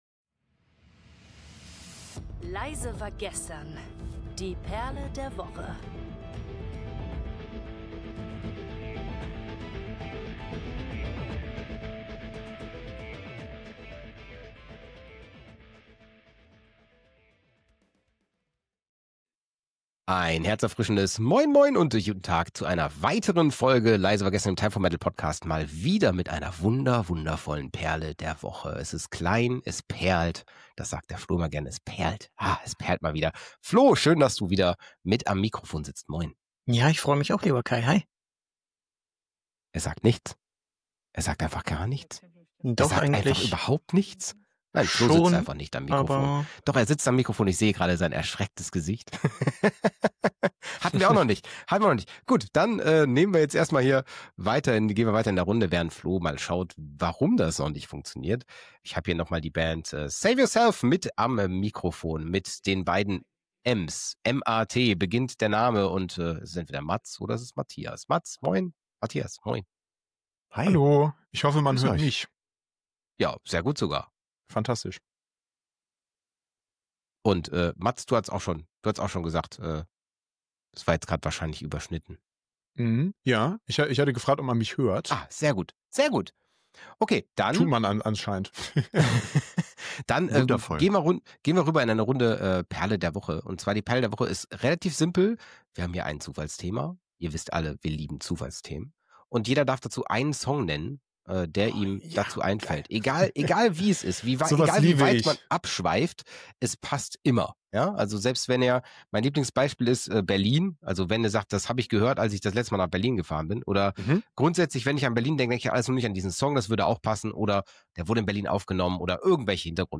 Als musikalischer Abschluss gibt’s eine echte Perle fürs Outro.